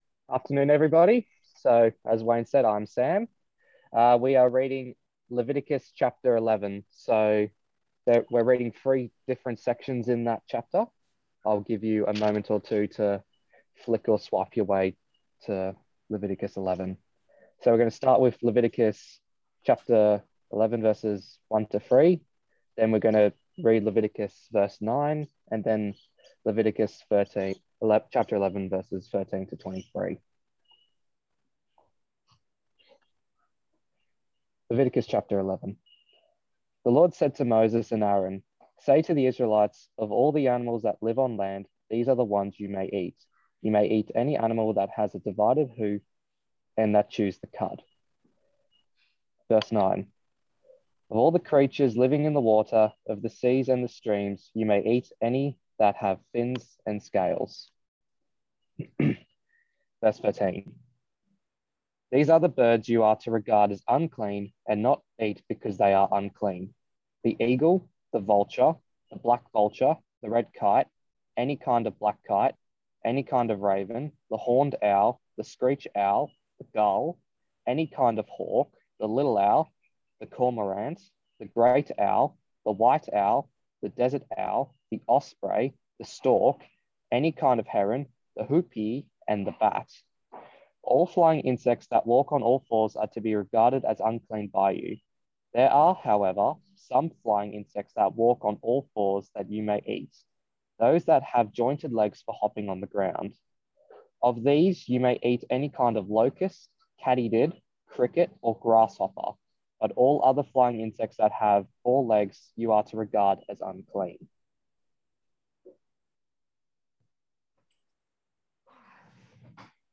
Passage: Leviticus 11:1-15:32 Talk Type: Bible Talk